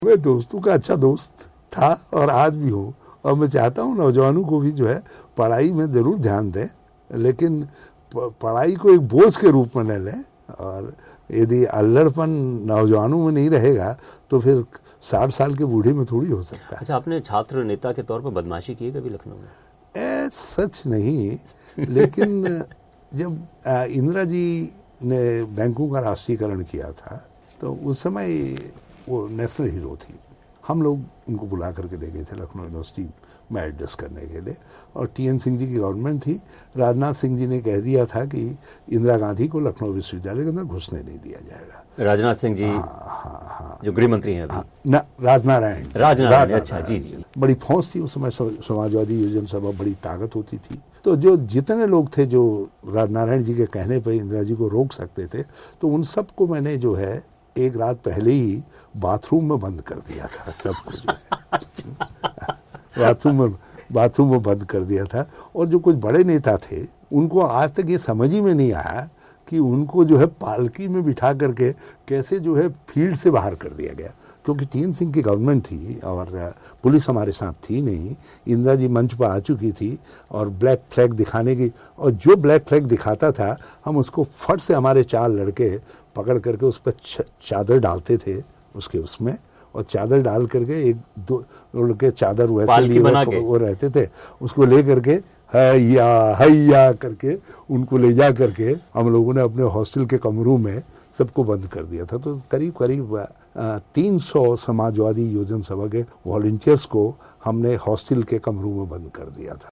उत्तराखंड के मुख्यमंत्री हरीश रावत ने बीबीसी के साथ ख़ास बातचीत में बताए अपने कॉलेज के कई किस्से.